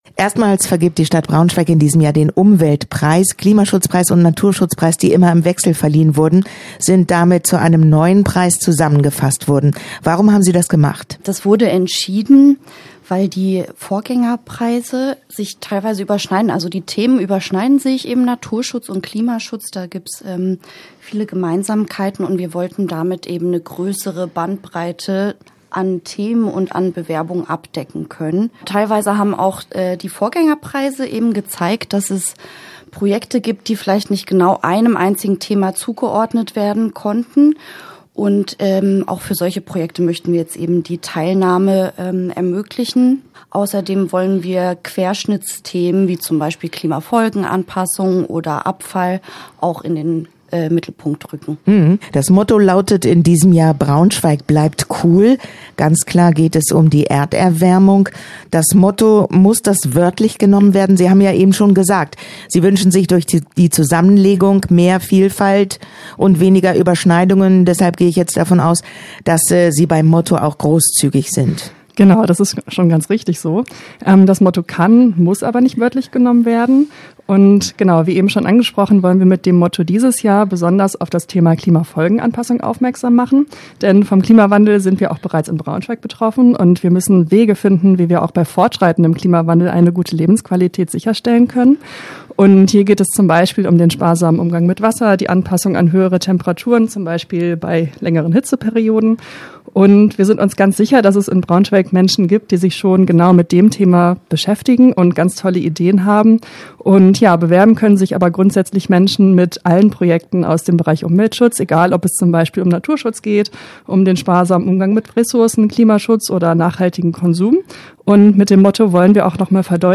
Interview-Umweltpreis-2025_nb.mp3